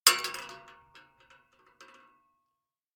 46265b6fcc Divergent / mods / Bullet Shell Sounds / gamedata / sounds / bullet_shells / shotgun_metal_3.ogg 48 KiB (Stored with Git LFS) Raw History
shotgun_metal_3.ogg